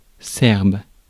Ääntäminen
Synonyymit servien Ääntäminen : IPA: /sɛʁb/ France: IPA: /sɛʁb/ Haettu sana löytyi näillä lähdekielillä: ranska Käännös Substantiivit 1. сръбски {m} (srǎbski) Adjektiivit 2. сръбски {m} (srǎbski) Suku: m .